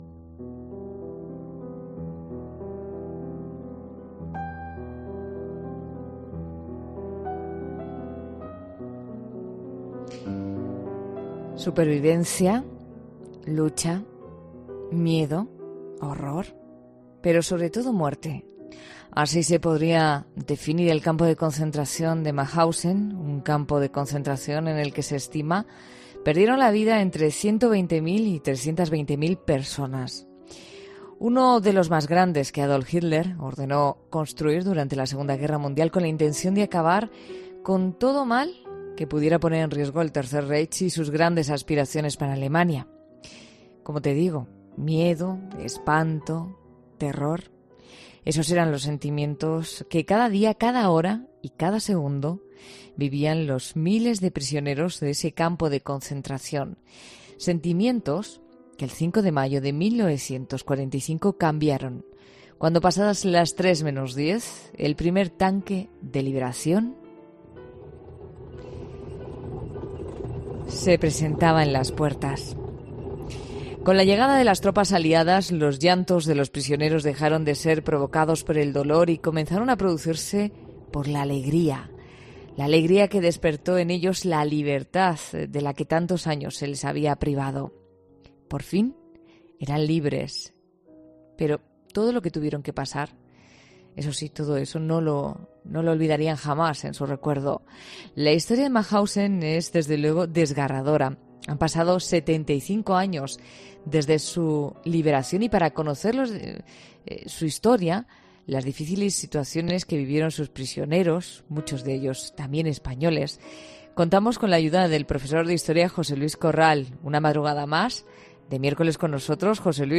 'La Noche' de COPE es un programa que mira la actualidad de cada día con ojos curiosos e inquietos, y en el que el tema principal de cada día, aquel del que todo el mundo habla, se ve desde un punto de vista distinto.